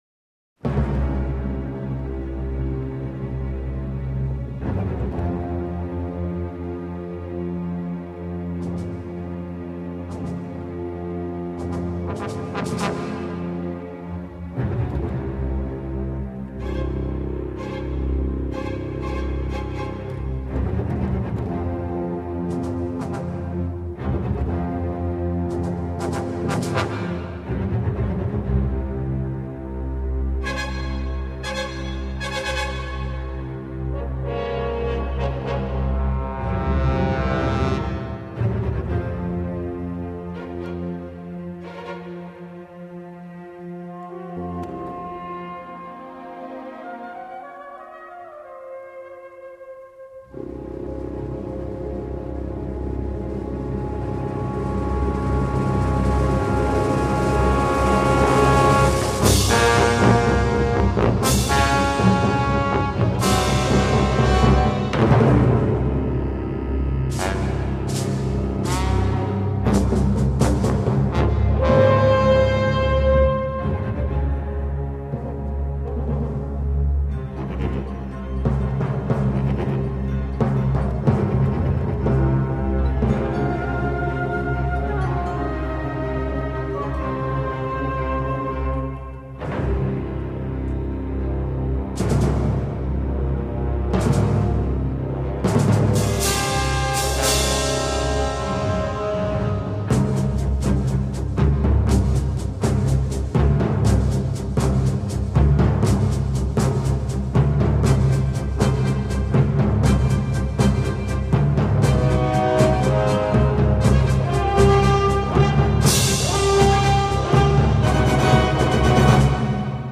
The suspenseful beginning.